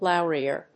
意味・対訳 ローリア； ローリエ； ロリエ、ロリエ